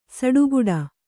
♪ saḍuguḍa